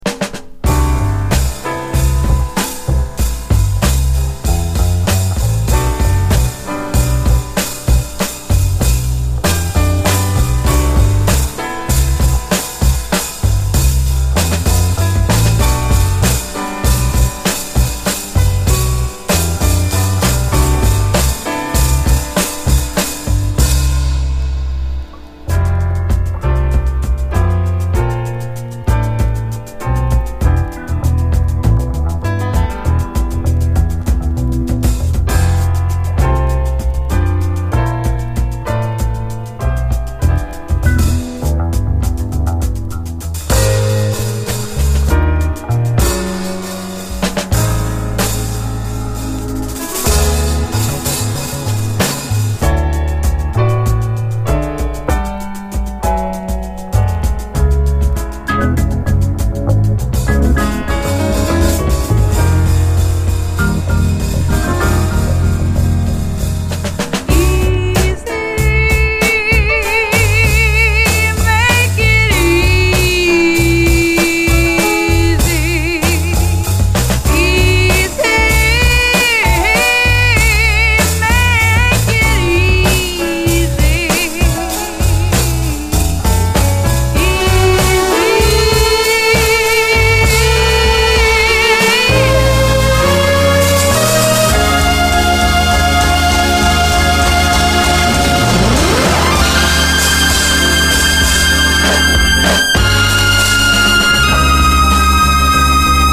R&B〜ソウル〜ラテン〜アフロなどを60’S末のサイケデリックな空気の元にミクスチャー！
フルートが甘く舞うメロウ・ファンク
STEREO、プロモ盤。